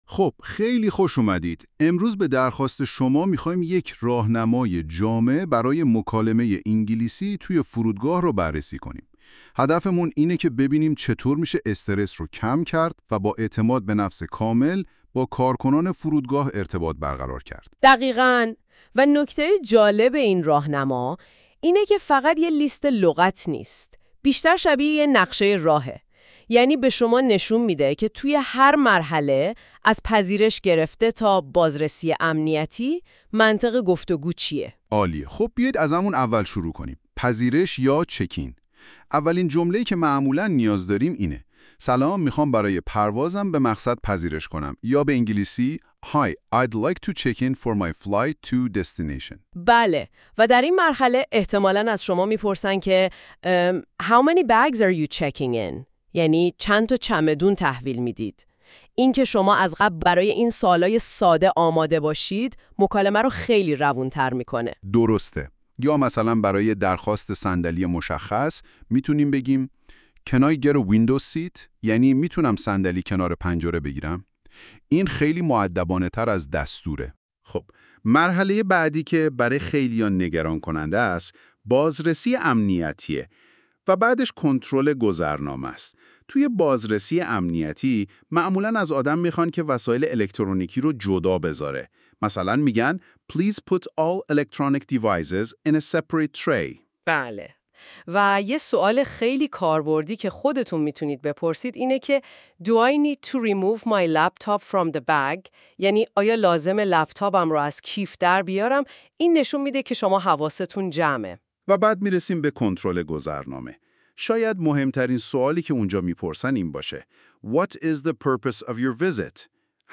Airport-English-conversation.mp3